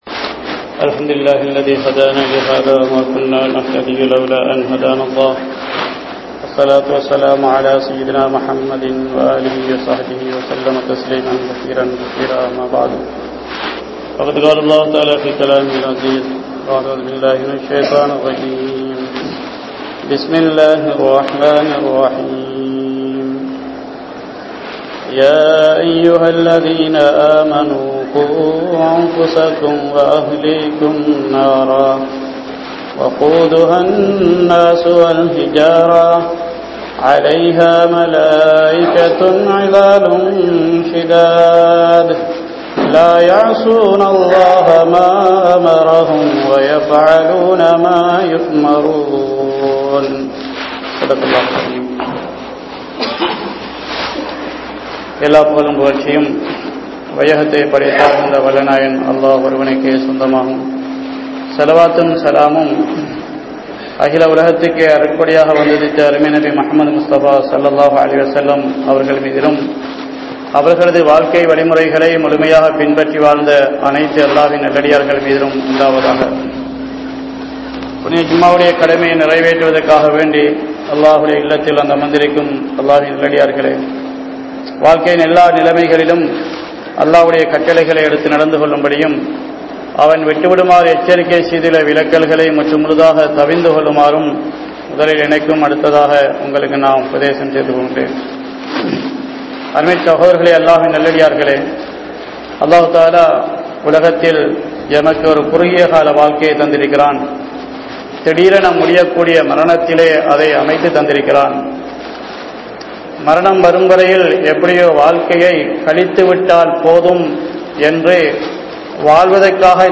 Paavaththin Meethu Ungalukku Inpamaa? (பாவத்தின் மீது உங்களுக்கு இன்பமா?) | Audio Bayans | All Ceylon Muslim Youth Community | Addalaichenai
Gallella Jumua Masjidh